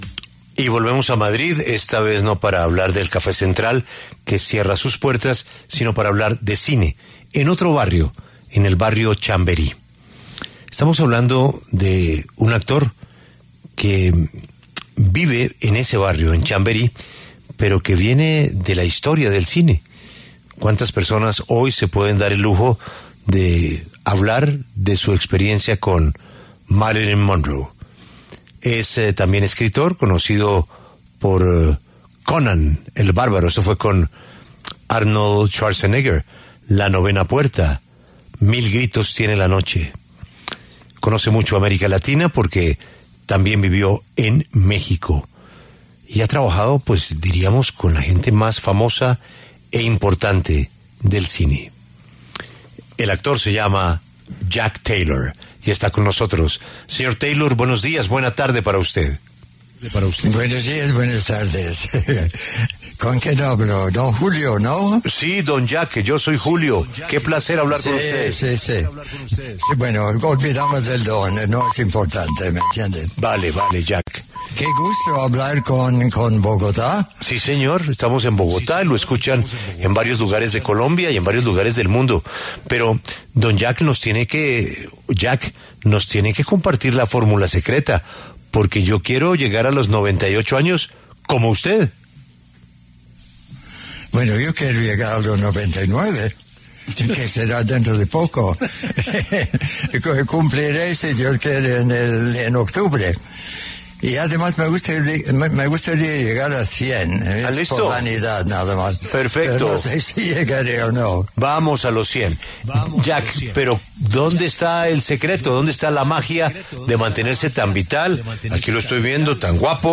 El actor estadounidense Jack Taylor, quien trabajó con personalidades como Marilyn Monroe, Arnold Schwarzenegger y Johnny Depp en Hollywood, conversó con La W sobre su carrera artística.